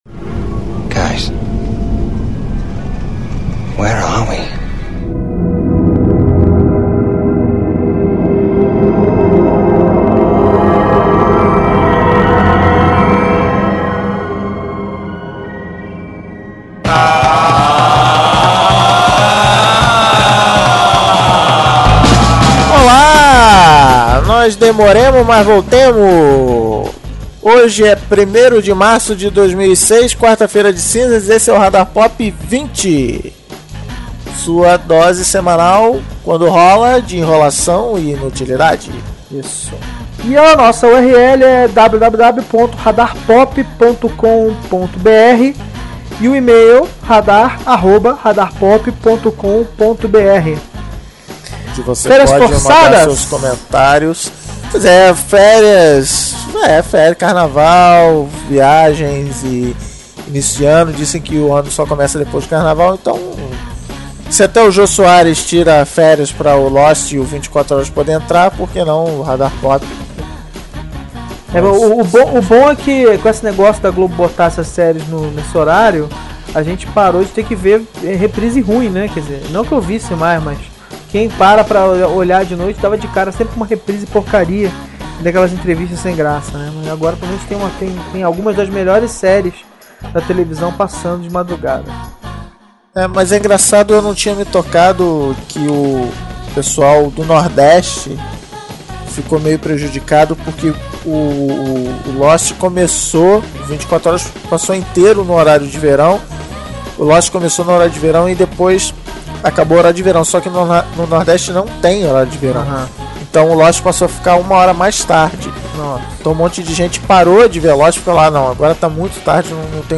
Tem um podcast de variedades bem legal chamado Radarpop que entrevistou uns caras do Lost . A entrevista � en ingl�s mas os apresentadores comentam em portugu�s. Tem spoilers pra quem ainda n�o est� vendo a 2� temporada.